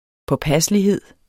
Udtale [ pʌˈpasəliˌheðˀ ]